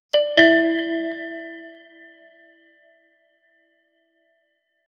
failed-to-choose-because--7lrzrerf.wav